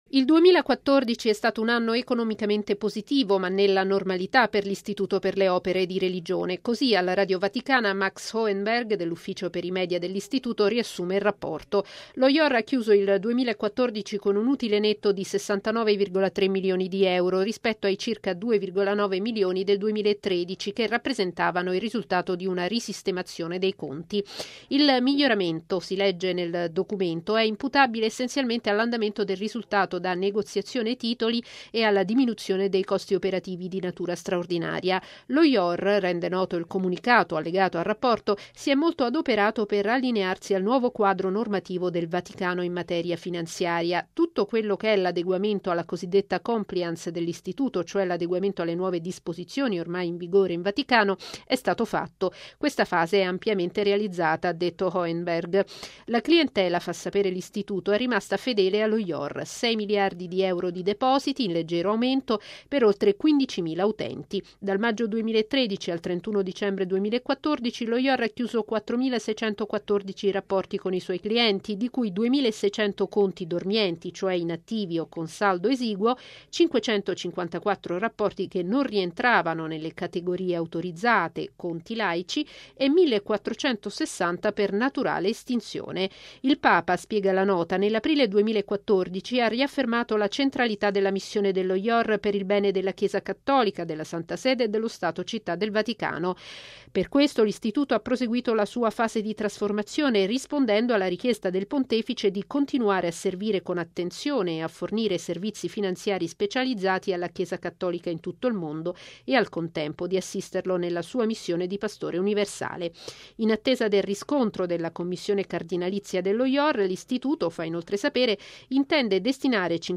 E’ quanto emerge dal Rapporto annuale 2014, pubblicato oggi dallo Ior, l'Istituto per le Opere di Religione. Il servizio